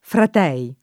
fratello [frat$llo] s. m. — tronc. davanti a cons., nel sign. di «frate»: fratel Giovanni [frat$l Jov#nni]; antiq. in altri casi: fratel mio [fratHl m&o] — elis. davanti a voc., di rado, nel sign. di «frate»: fratell’Anselmo (meglio che fratel Anselmo) — sim. i cogn.